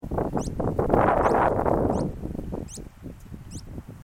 Lenheiro-de-rabo-comprido (Asthenes pyrrholeuca)
Encontrado entre la vegetación palustre de la laguna Culú Culú.
Partido de Lobos, Provincia de Buenos Aires.
Nome em Inglês: Sharp-billed Canastero
Condição: Selvagem
Certeza: Fotografado, Gravado Vocal